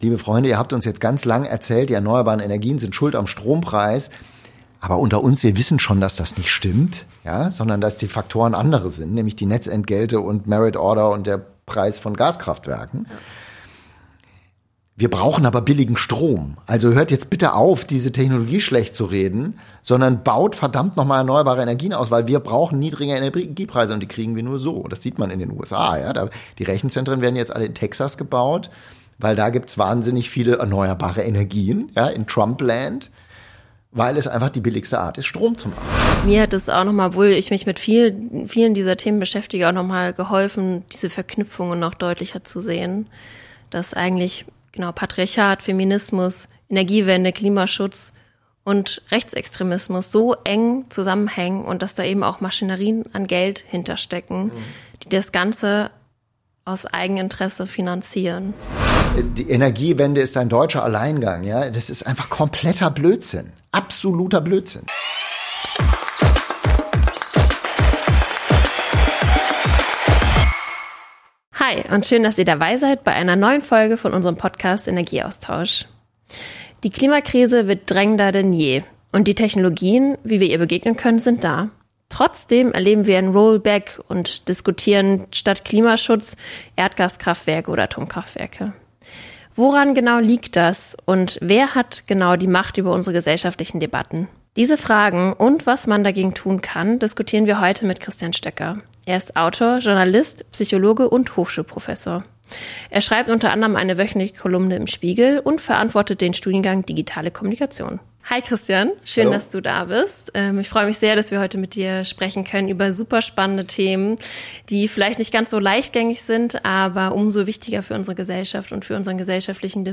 Eine Folge über Klimapolitik, Machtstrukturen und die zentrale Frage, wer unsere gesellschaftlichen Debatten lenkt. Aufgezeichnet wurde das Gespräch am 18. November 2025 Mehr